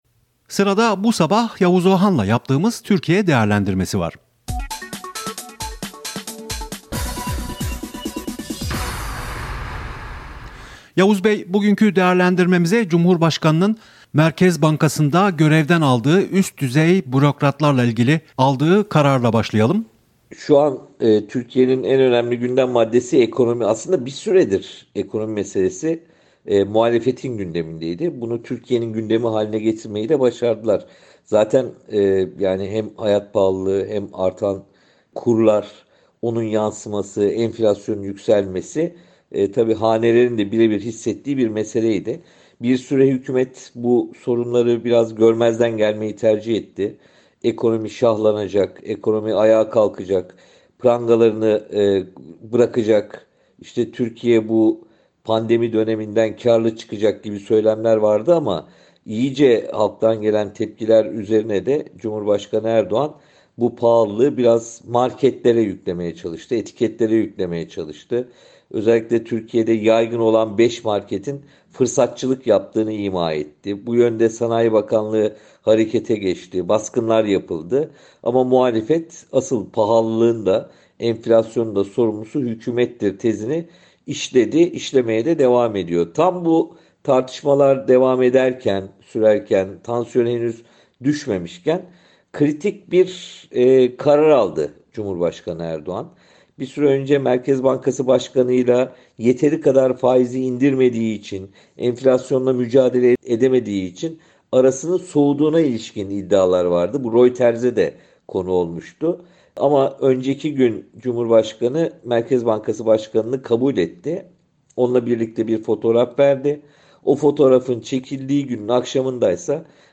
Gazeteci Yavuz Oğhan Türkiye'de öne çıkan konuları değerlendirdi.
Öne çıkanlar Yavuz Oğhan, Cumhurbaşkanının Merkez Bankası’nda görevden aldığı üst düzey bürokratlar, TÜGVA Vakfı tartışmaları, ve muhalefet lideri Kılıçdaroğlu’nun siyasi cinayetlerin artabileceği uyarısına ilişkin sorularımızı yanıtladı.